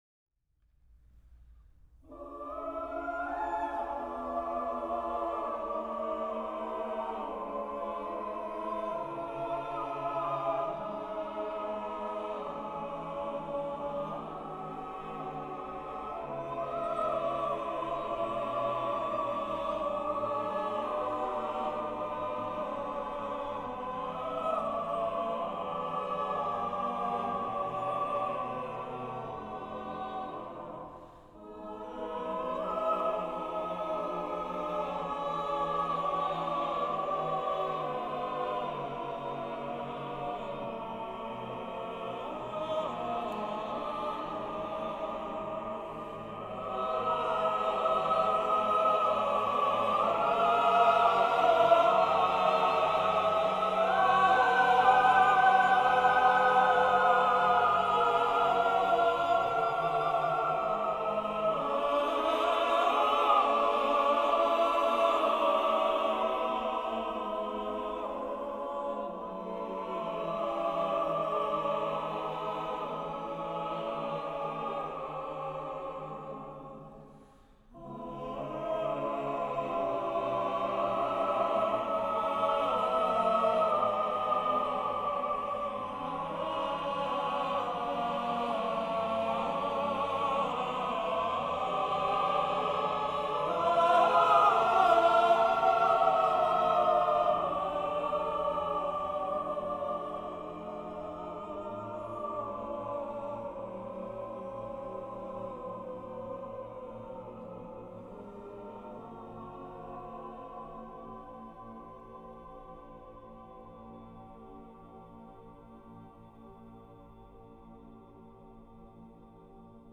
During the period between the composition and premiere of The Song of the High Hills, Delius would once again turn to dramatic vocalization in the two wordless songs To Be Sung of a Summer Night on the Water (1917).
At the opening, the sopranos sing the main melodic idea of the first song over the accompaniment of the other voices.
The first of the two songs has a melodic contour and sorrowful mood similar to the theme of The Song of the High Hills.